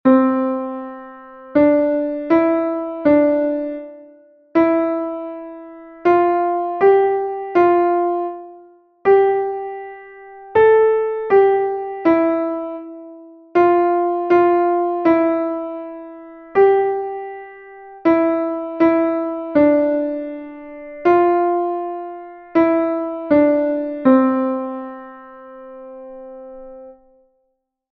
Here, there are two exercises in a 2/4 and 3/4 time signatures.